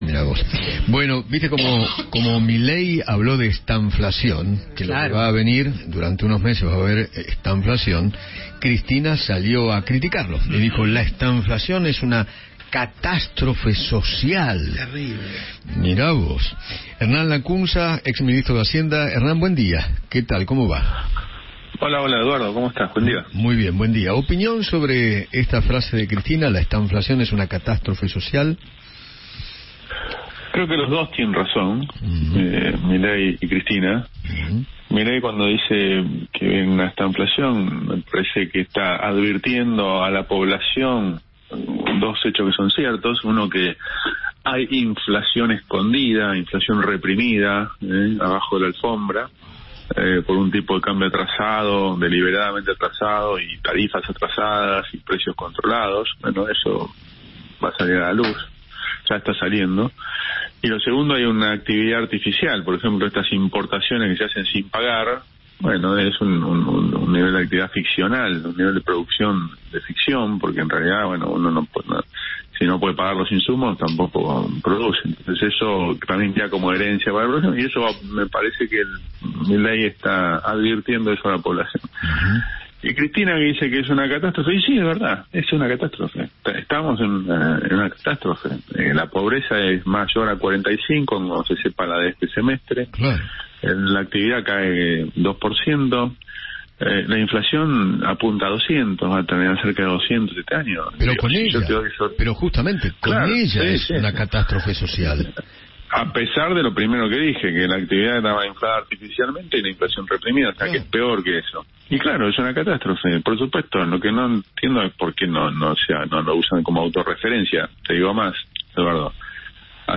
Hernán Lacunza, ex ministro de Hacienda, conversó con Eduardo Feinmann sobre la estanflación que anticipó Javier Milei para los próximos meses.